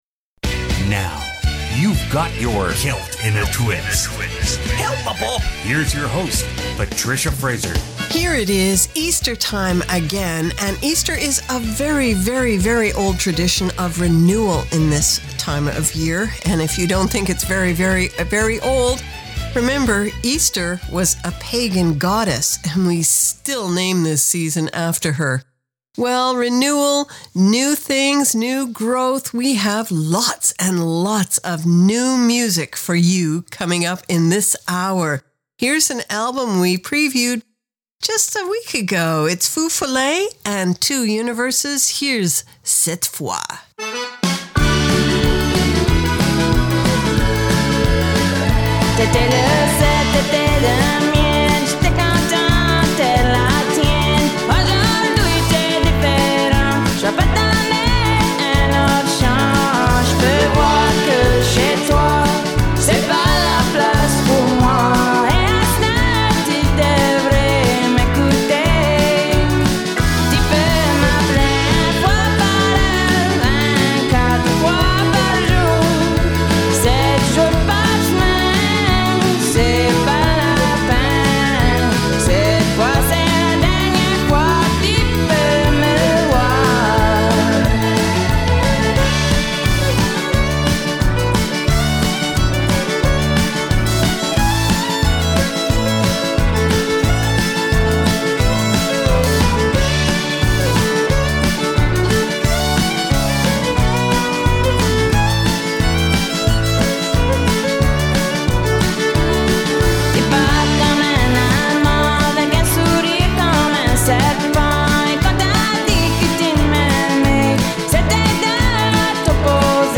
Canada's Contemporary Celtic Radio Hour
File Information Listen (h:mm:ss) 0:59:56 Celt In A Twist April 5 2015 Download (8) Celt_In_A_Twist_April_05_2015.mp3 71,933k 0kbps Stereo Comments: An hour of new Celtic spins sandwiched between spicy slices of new Cajun.